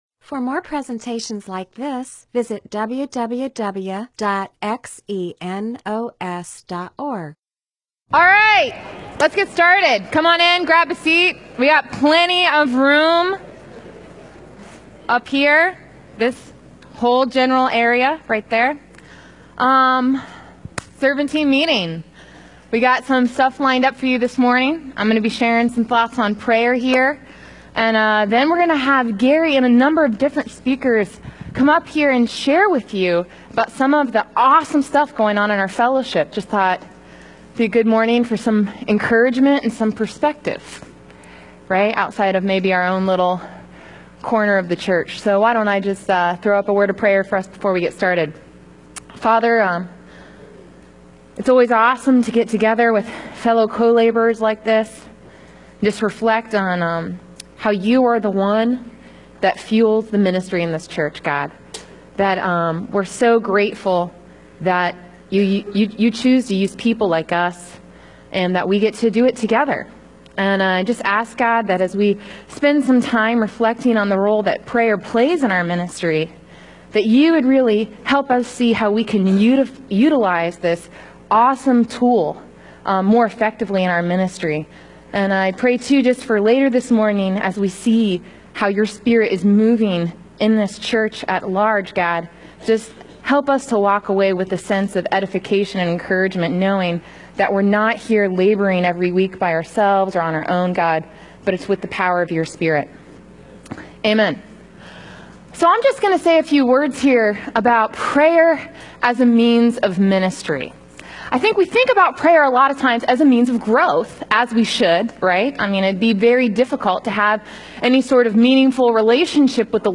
Bible teaching (presentation, sermon) on , Title: Prayer as a Means of Ministry